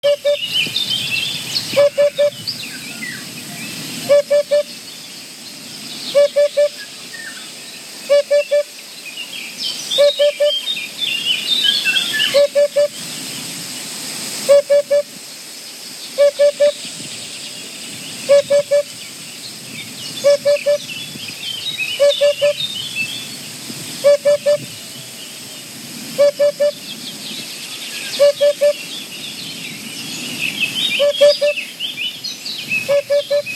Pupăza (Upupa epops)
Face un sunet inconfundabil: „pu-pu-pu”, de unde vine și numele ei.
Ascultă chemarea pupăzei!
Pupaza.m4a